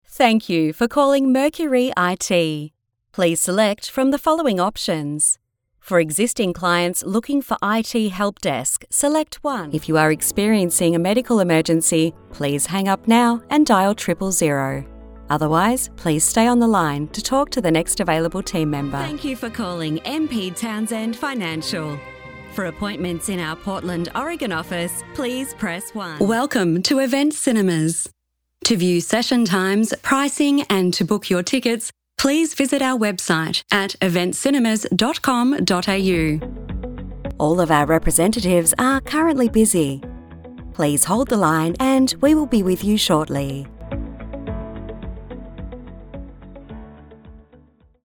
0208IVR_On_Hold.mp3